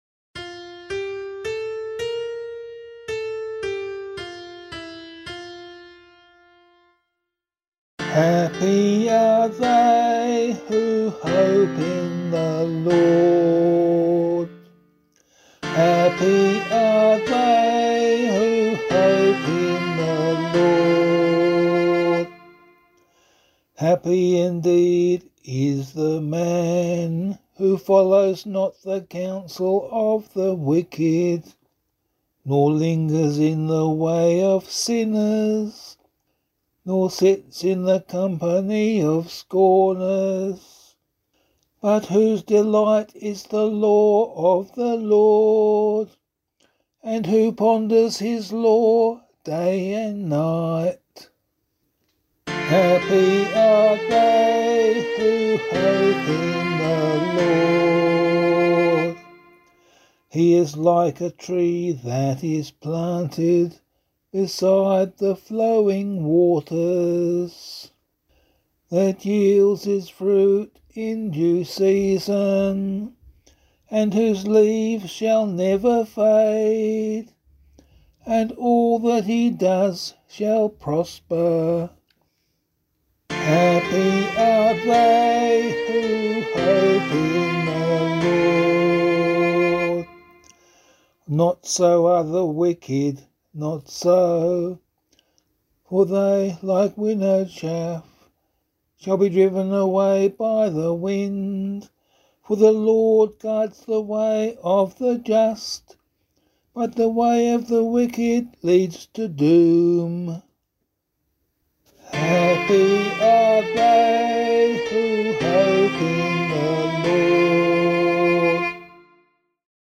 040 Ordinary Time 6 Psalm C [LiturgyShare 1 - Oz] - vocal.mp3